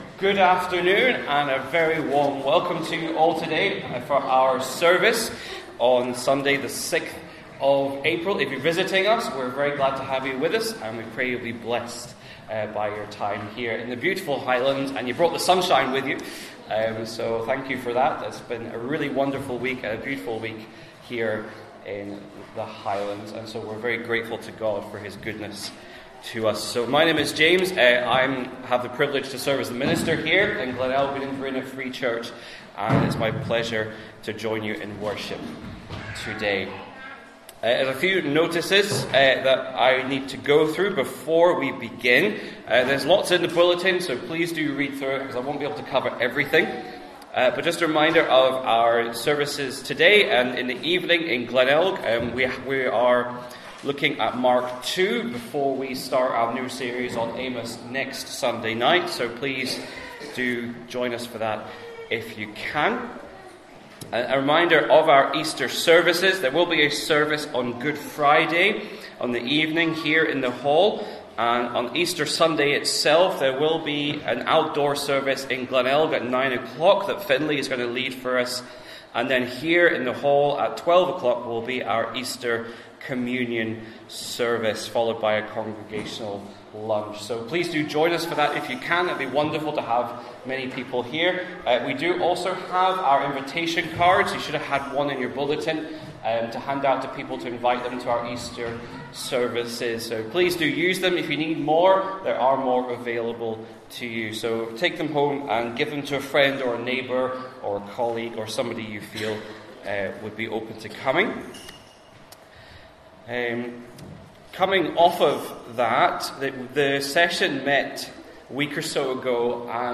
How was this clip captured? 12-Noon-Service-.mp3